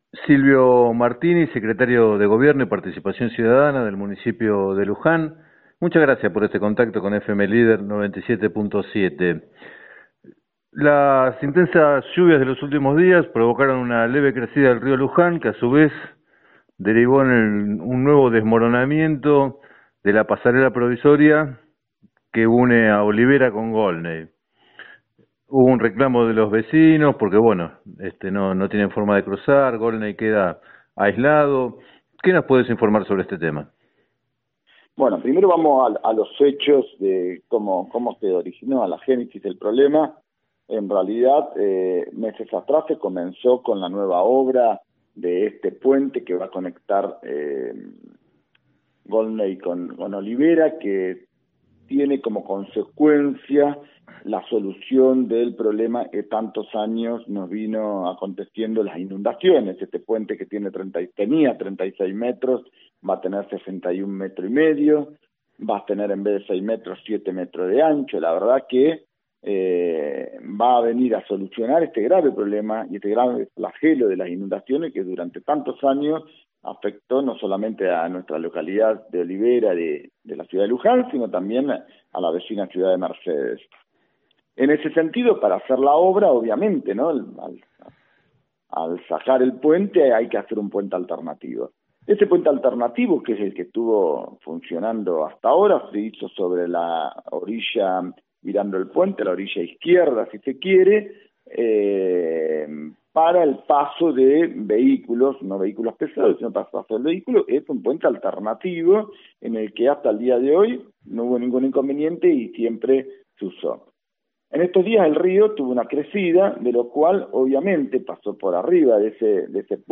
En declaraciones al programa “7 a 9, Música y Noticias” de FM Líder 97.7, Silvio Martini, secretario de Gobierno y Participación Ciudadana del Municipio, destacó la importancia de la obra de construcción de un nuevo puente en el lugar para mitigar las inundaciones.